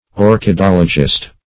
Orchidologist \Or`chid*ol"o*gist\, n. One versed in orchidology.
orchidologist.mp3